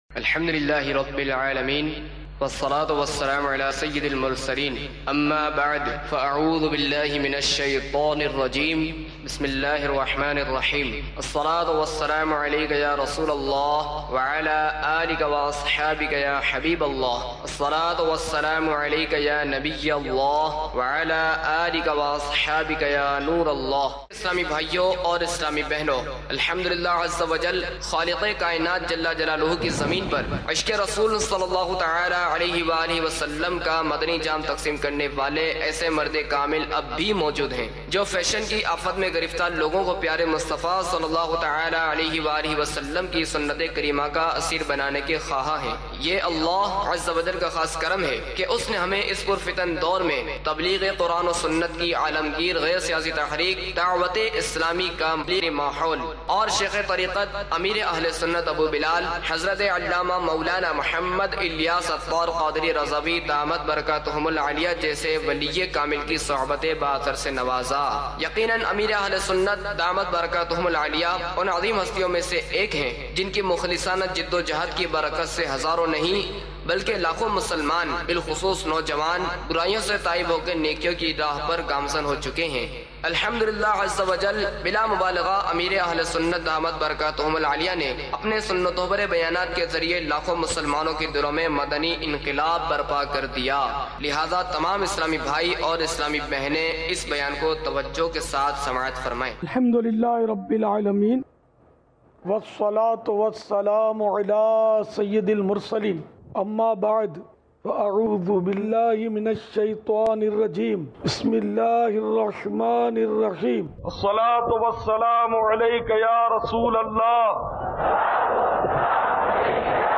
Ameer Ahlesunnat Ka Audio Bayan - Gane, Sharab Aur Tohmat Ki Sazayein - English Subtitled (15-Jamad Ul Ukhra 1423) Mar 1, 2025 MP3 MP4 MP3 Share امیر اہلسنت کا آڈیو بیان - گانے ،شراب اور تہمت کی سزائیں - انگلش سب ٹائٹل